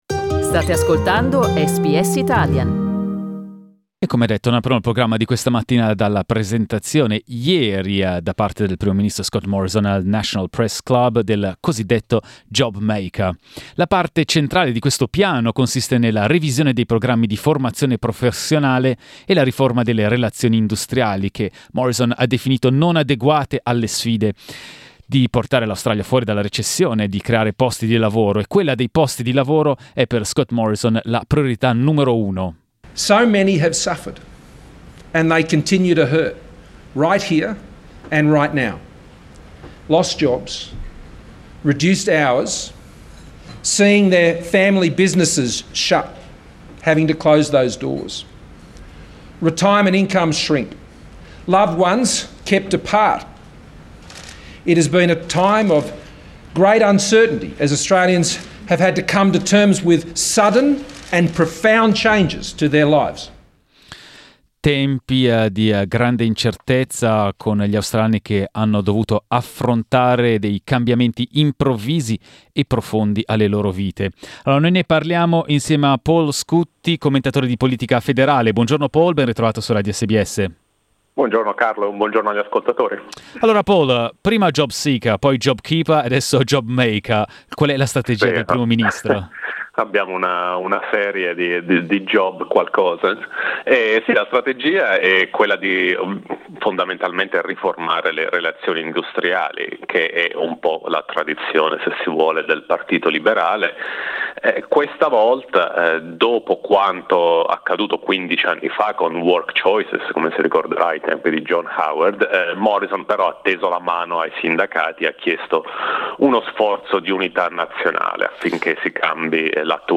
The central part of the plan consists of a review of vocational training and TAFE programs and the reform of industrial relations, which he called "inadequate" to bring Australia out of the crisis and create jobs. We talked about it with federal politics correspondent